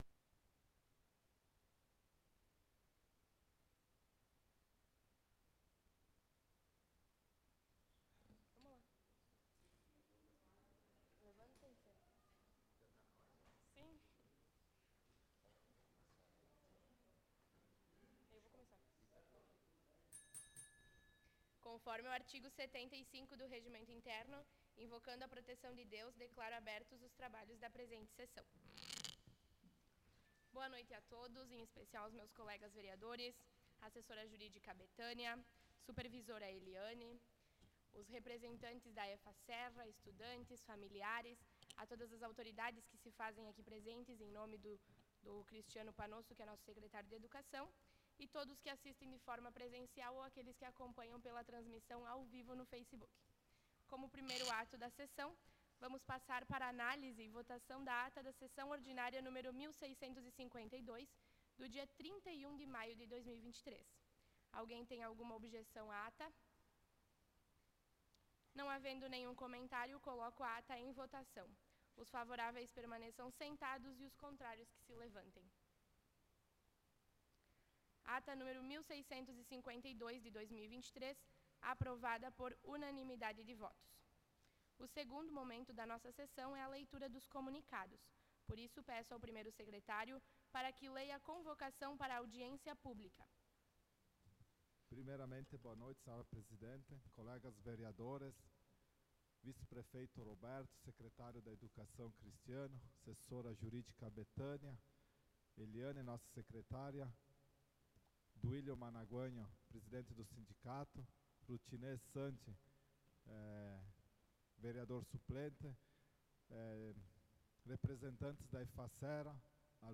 Sessão Ordinária do dia 07/06/2023